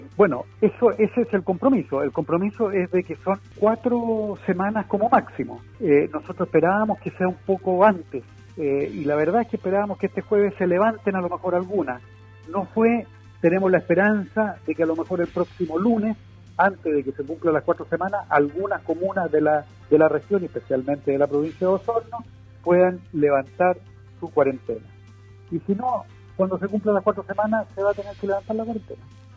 En entrevista con radio Sago, el Intendente de la región de Los Lagos, Carlos Geisse, se refirió al proceso de vacunación masiva que comienza este miércoles 03 de febrero, recordando que a la zona arribaron 86 mil dosis.